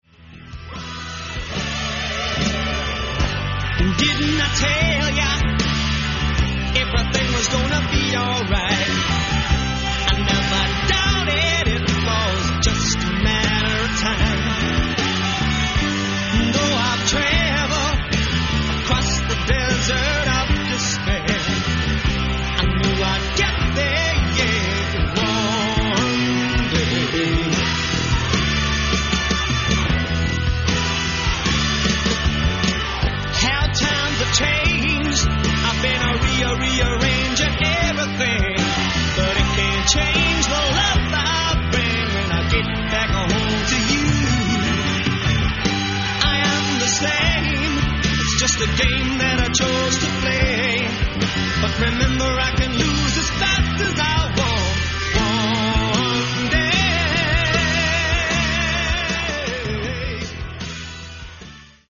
The Chateau D'Herouville, France